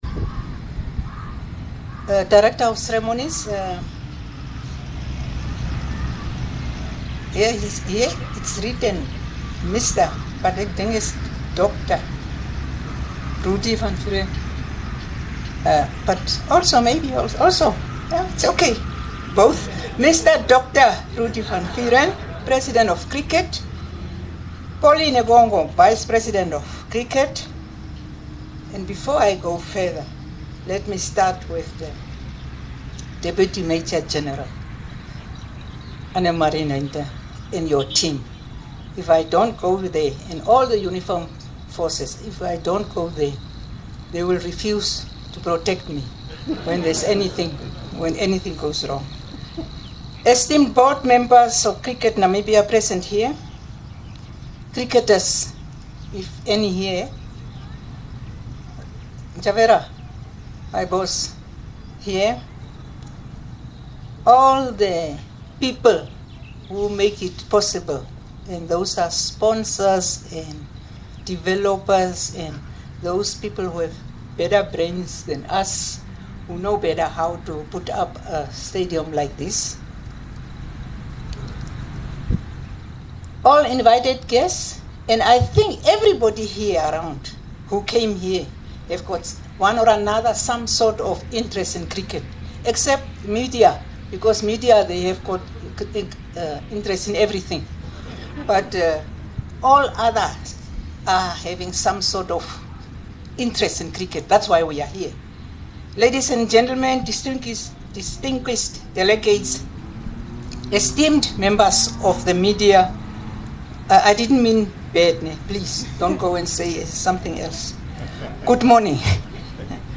20 Mar Minister of Sports speaks at the National Cricket Stadium groundbreaking
The Honorable Minister of Sport, Youth and National Service was the keynote speaker at the event this morning.